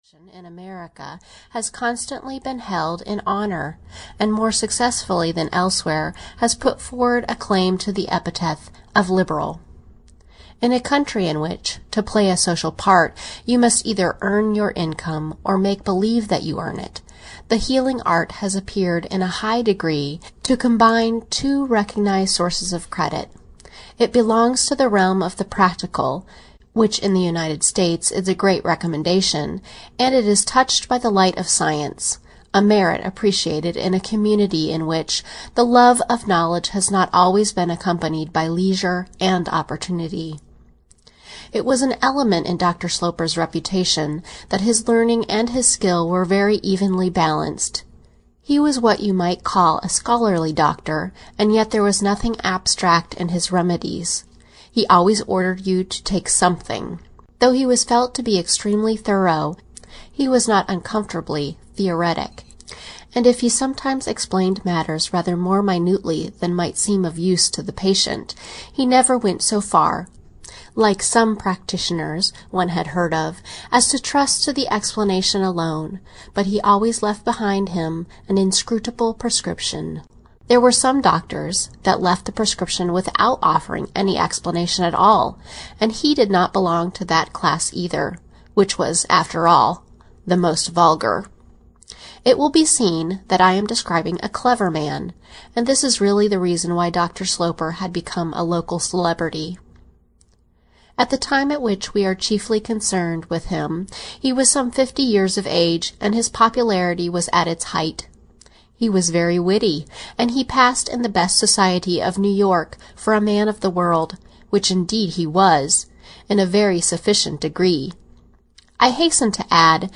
Washington Square (EN) audiokniha
Ukázka z knihy